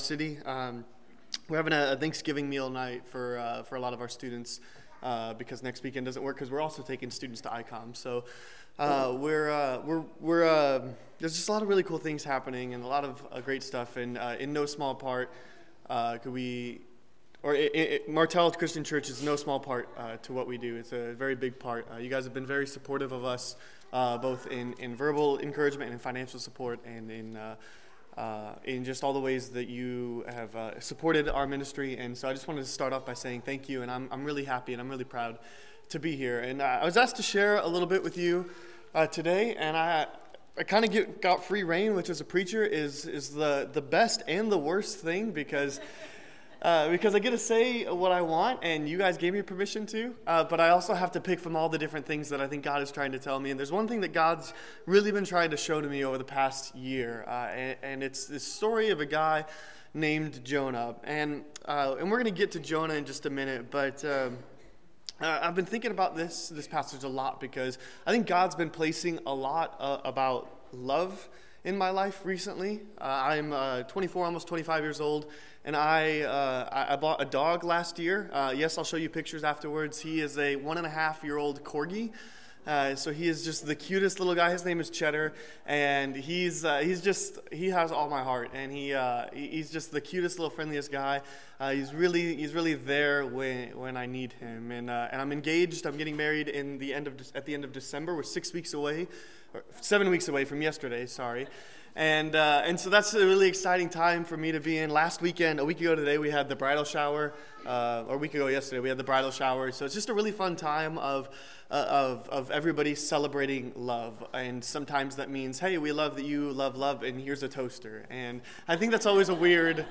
Single Passage: Jonah 1:1-2, 13-14, 3:4-10, 4:2-4, 9, 2Kings 14:25 Service Type: Sunday Morning Topics